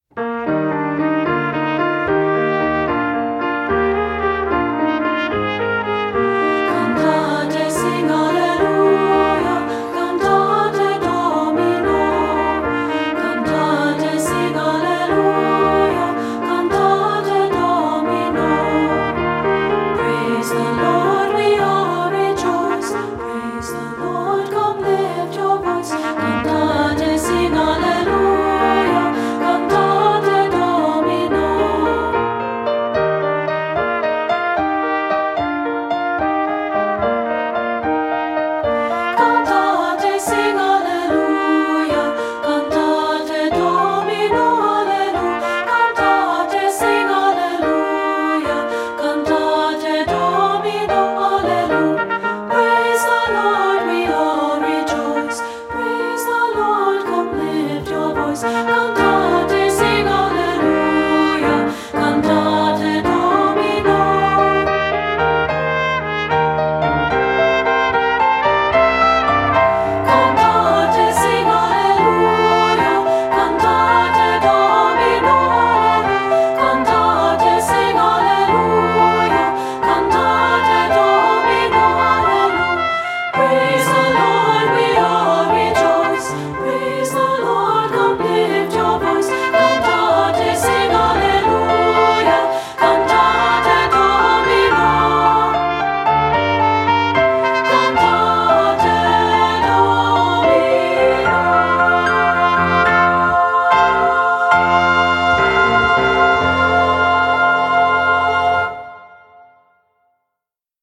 secular choral
- 2-part, sample